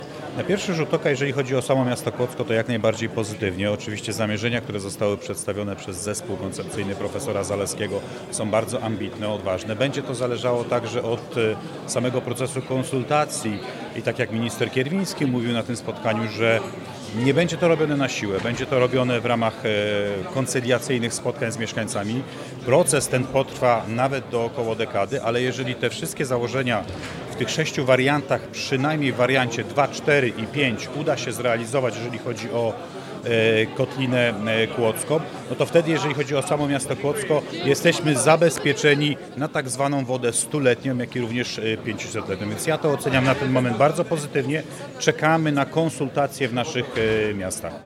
– Na pierwszy rzut oka bardzo pozytywnie – mówi Michał Piszko, burmistrz Kłodzka.